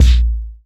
NOISE KICK.wav